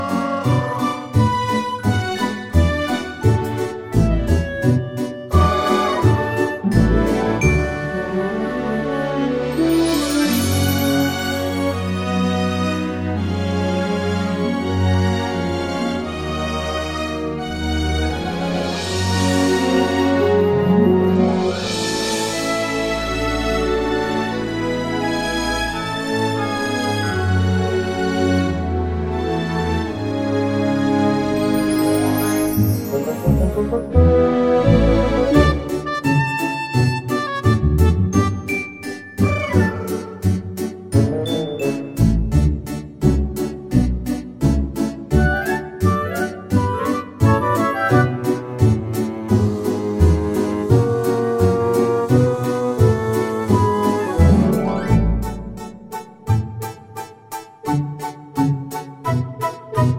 no Backing Vocals Soundtracks 2:09 Buy £1.50